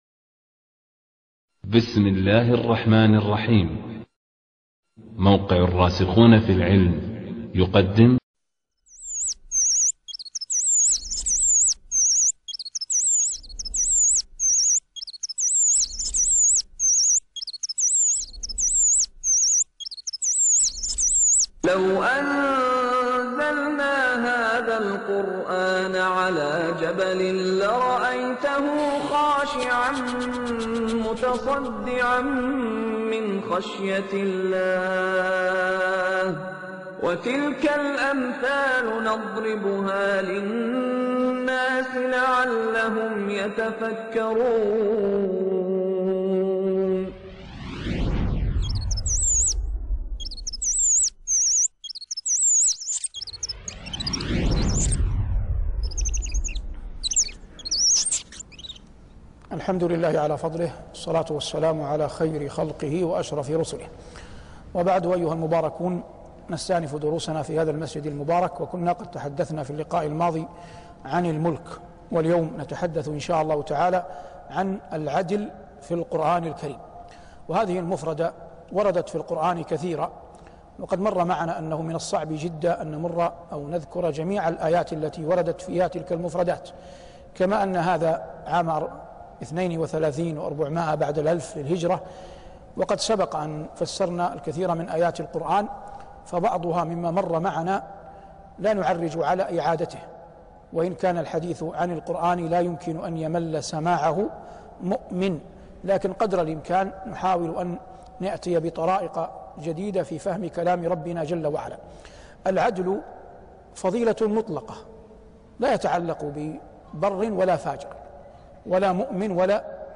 شبكة المعرفة الإسلامية | الدروس | العدل فى القرآن |صالح بن عواد المغامسي